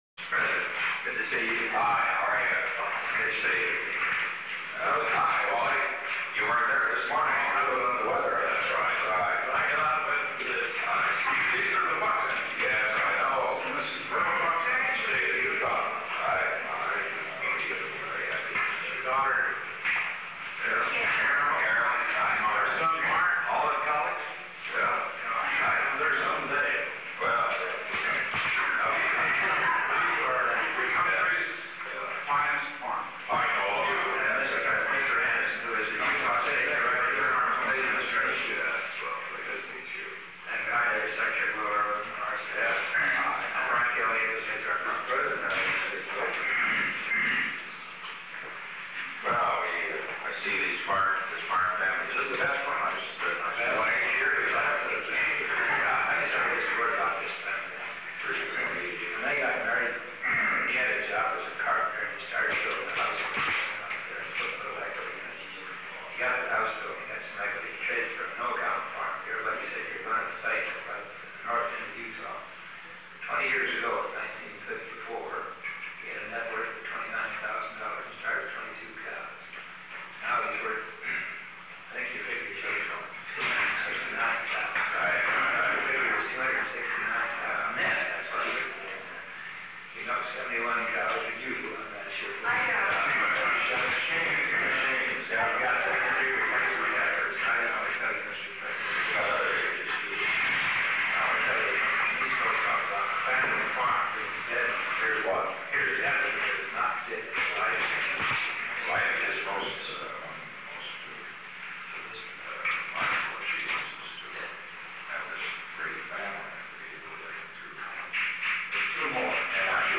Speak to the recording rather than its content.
Secret White House Tapes Location: Oval Office photographer and members of the press were present at the beginning of the meeting.